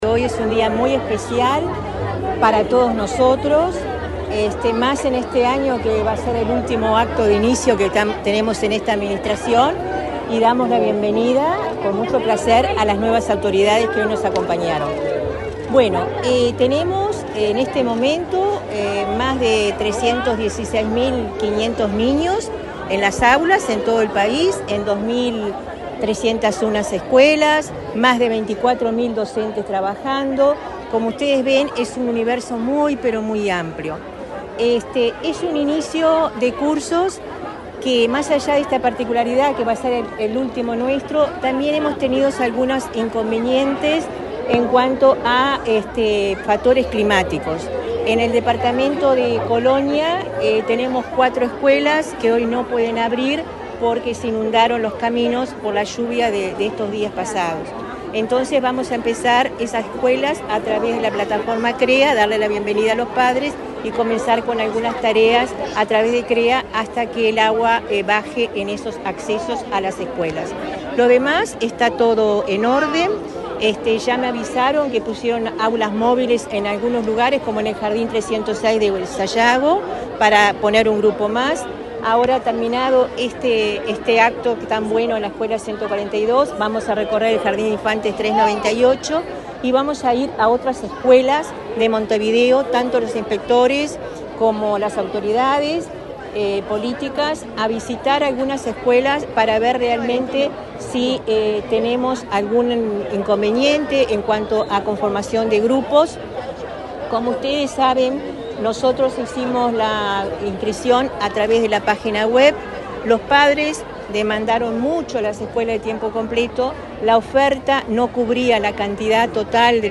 Declaraciones de la directora general de Primaria, Olga de las Heras
La directora general de Educación Inicial y Primaria, Olga de las Heras, dialogó con la prensa, durante la recorrida que realizó, este miércoles 5,